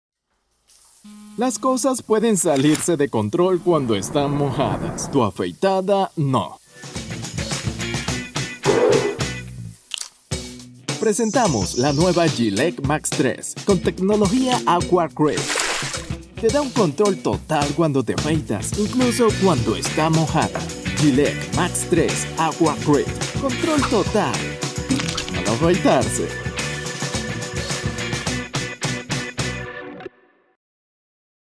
Sprechprobe: Industrie (Muttersprache):
I will recording spanish male voice over.